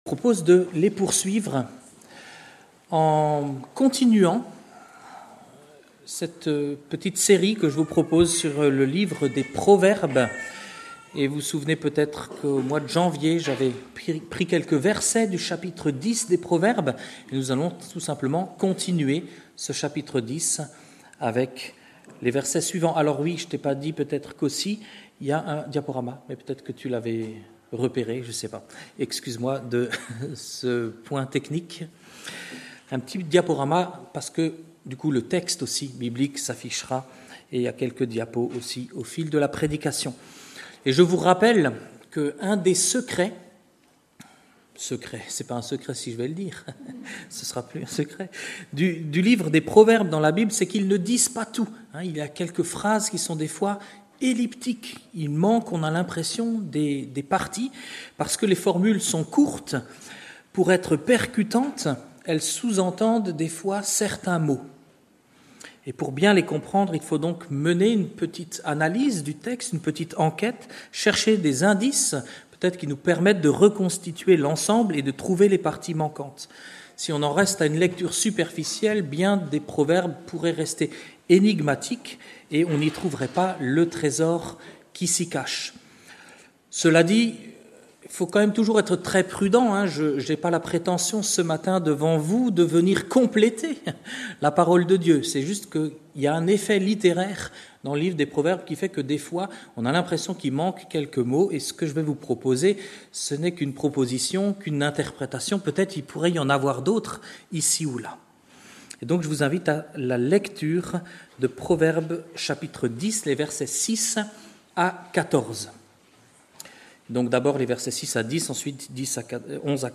Culte du dimanche 23 février 2025 – Église de La Bonne Nouvelle